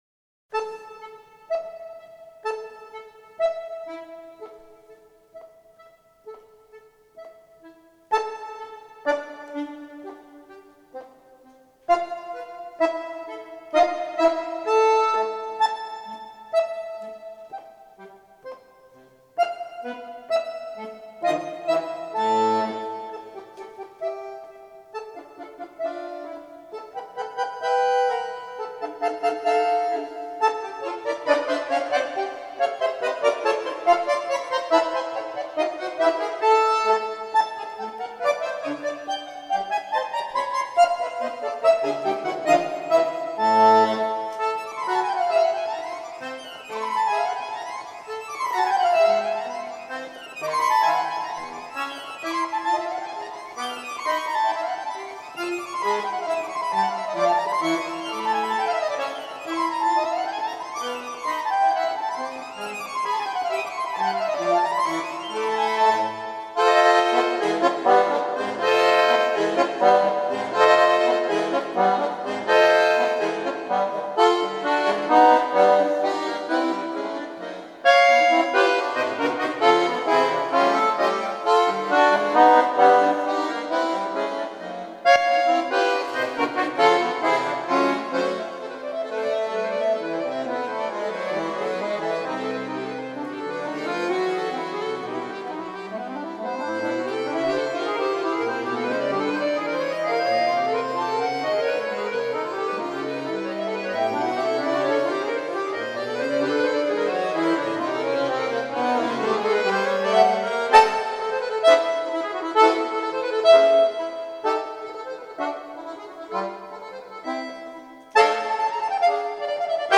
for 2 accordions | per 2 fisarmoniche